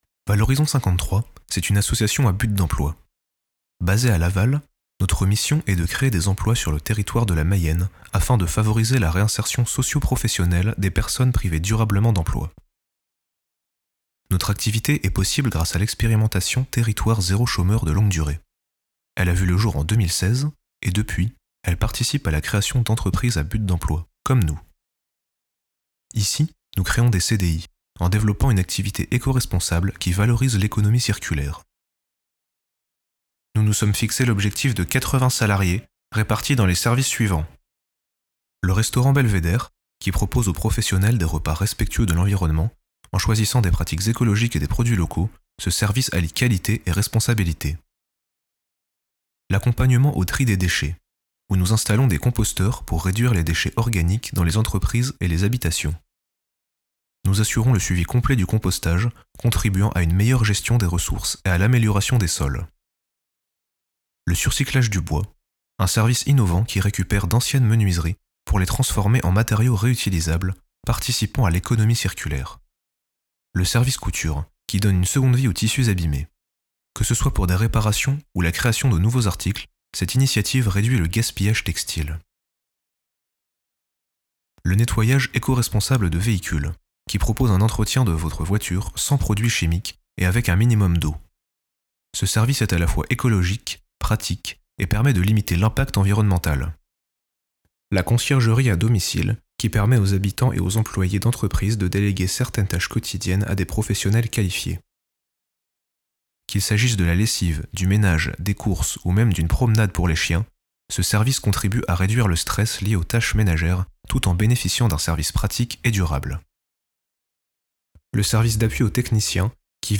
Voix_off_video.mp3